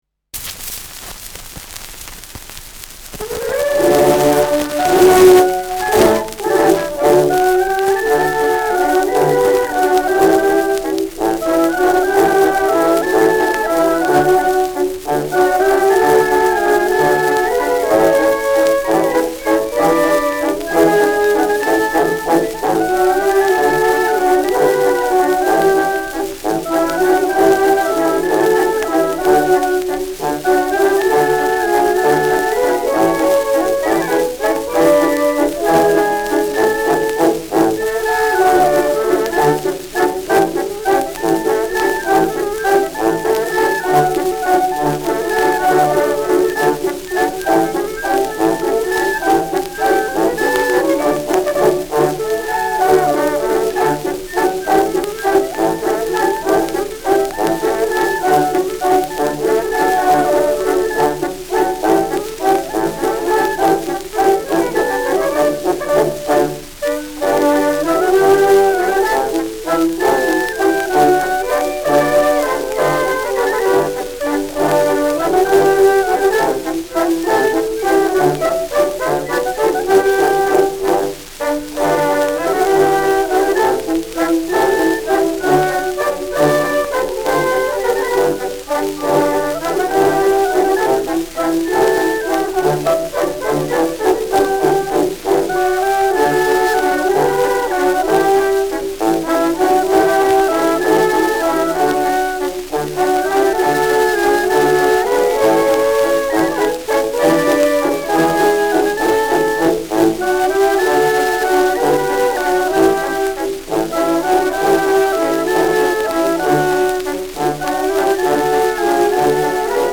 Schellackplatte
präsentes Rauschen : abgespielt : präsentes Knistern : gelegentliches Schnarren : vereinzeltes Knacken : leiert
Bayrische Ländler-Kapelle Favorite (Interpretation)